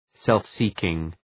Προφορά
{,self’si:kıŋ}